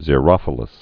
(zĭ-rŏfə-ləs)